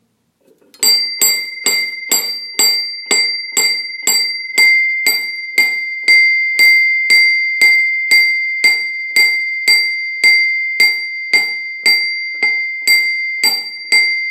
Litinový zvonek jelen 22x14,5x27cm
Litinový zvonek jelen na stěnu ke dveřím.